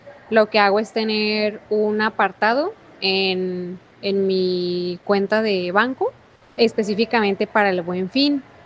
SONDEO-1.wav